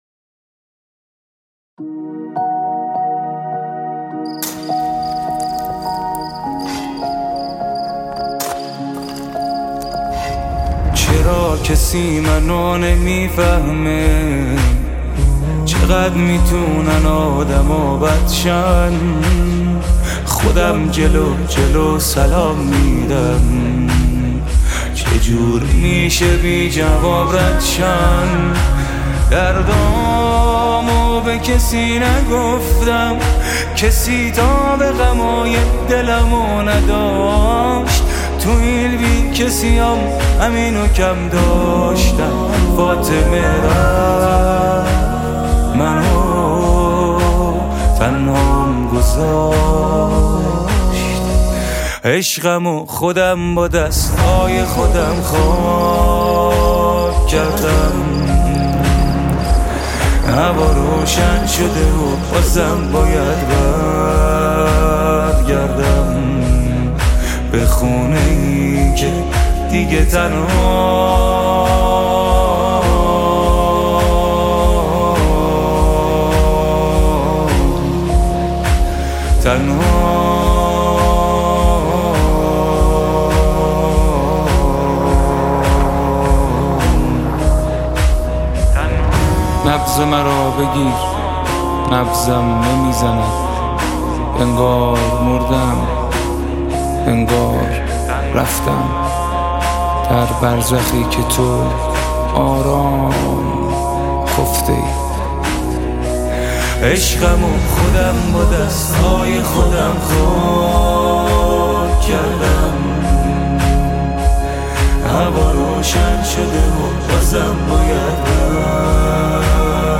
مداحی استودیویی